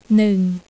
It is a single syllable, so it is neither nee or ung; nor is it saying them together very fast.
neung.wav